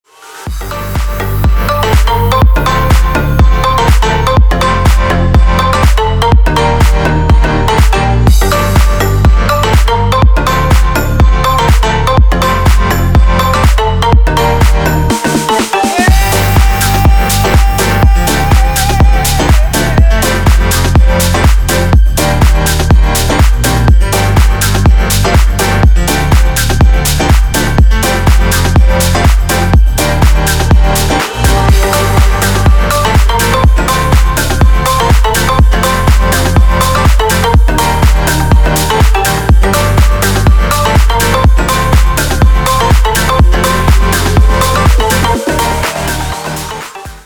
future house
club
house
Indie Dance